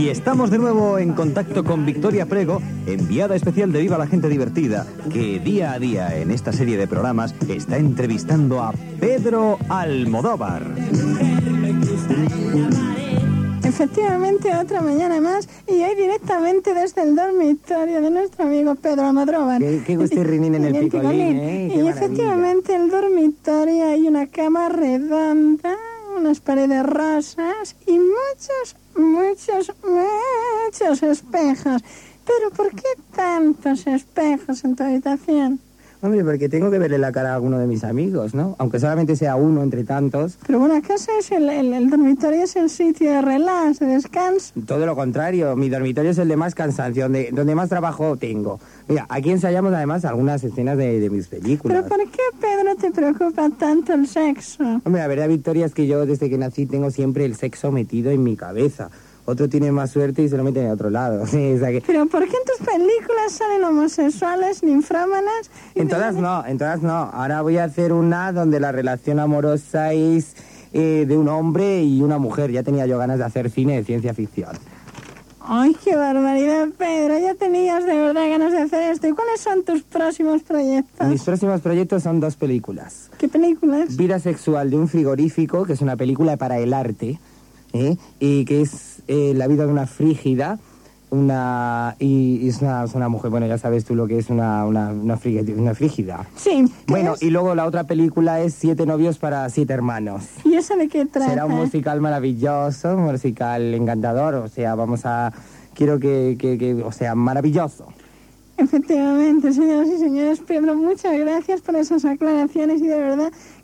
Imitacions de Victoria Prego entrevistant a Pedro Almodóvar